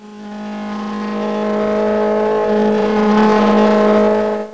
buzz4.mp3